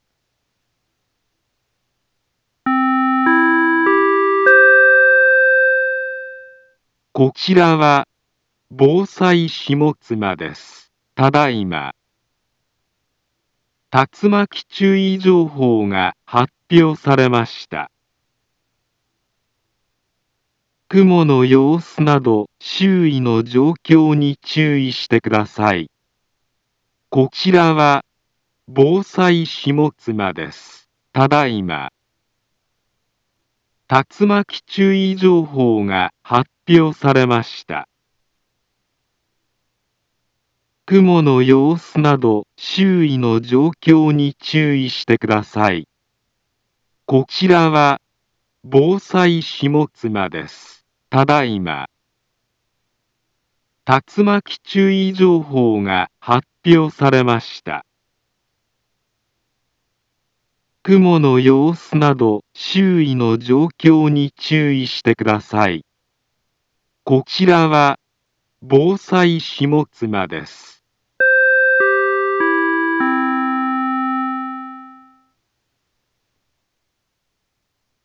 Back Home Ｊアラート情報 音声放送 再生 災害情報 カテゴリ：J-ALERT 登録日時：2025-03-24 23:14:49 インフォメーション：茨城県南部は、竜巻などの激しい突風が発生しやすい気象状況になっています。